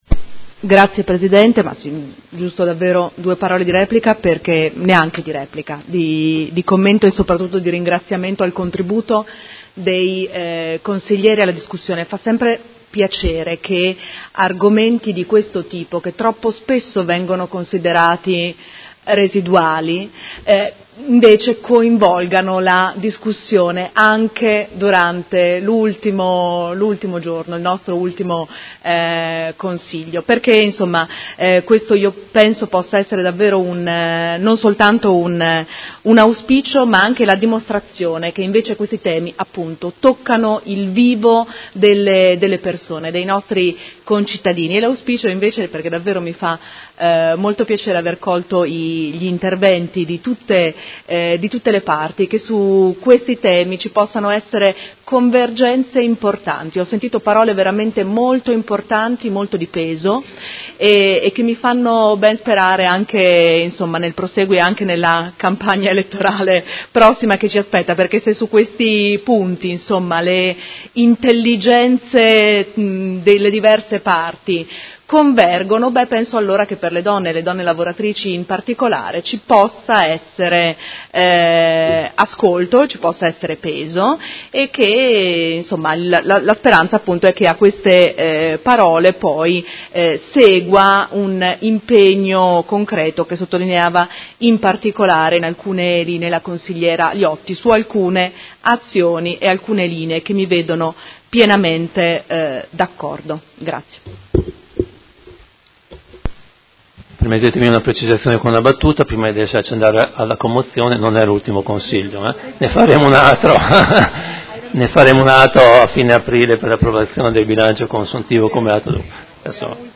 Irene Guadagnini — Sito Audio Consiglio Comunale
Seduta del 04/04/2019 Conclusioni. Interrogazione dei Consiglieri Malferrari, Cugusi e Stella (SUM) avente per oggetto: Diritti negati e molestie sessuali nei luoghi di lavoro a Modena e Provincia a danno delle donne.